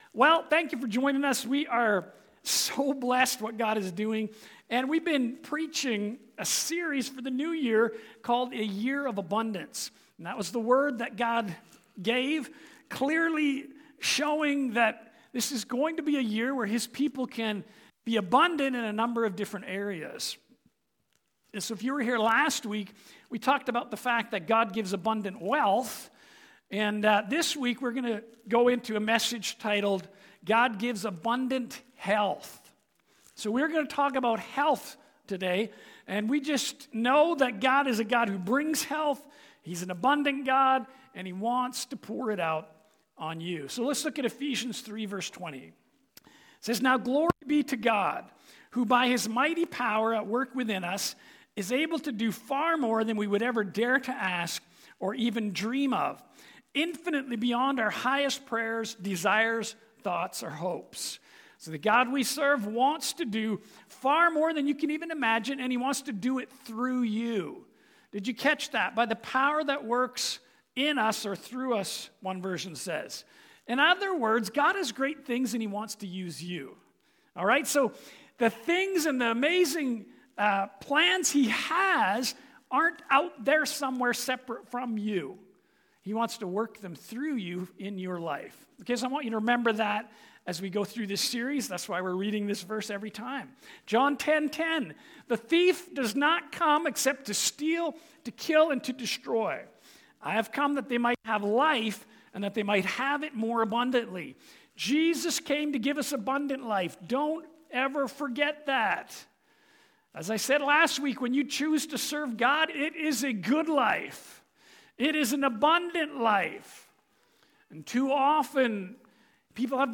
Sermons | Abundant Life Worship Centre